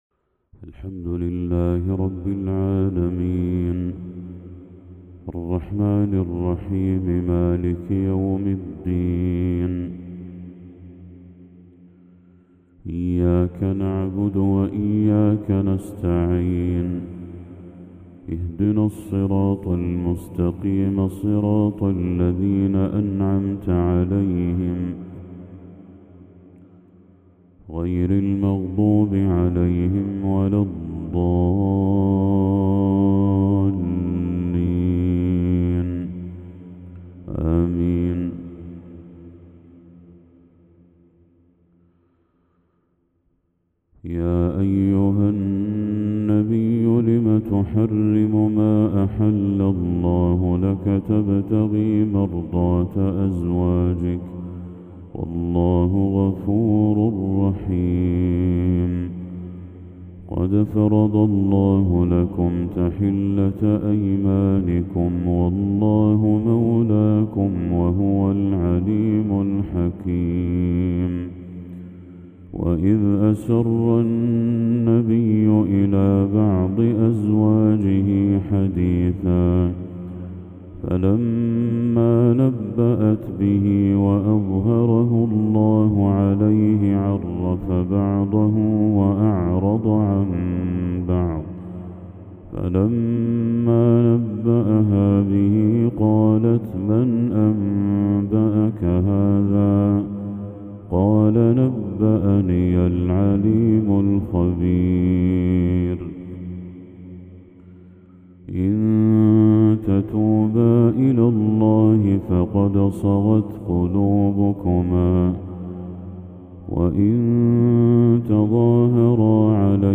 تلاوة خاشعة للشيخ بدر التركي سورة التحريم كاملة | فجر 4 ذو الحجة 1445هـ > 1445هـ > تلاوات الشيخ بدر التركي > المزيد - تلاوات الحرمين